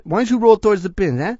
l_rolltowardsthepins.eh.wav